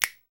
Index of /90_sSampleCDs/Roland L-CD701/PRC_Clap & Snap/PRC_Snaps
PRC SNAPS 02.wav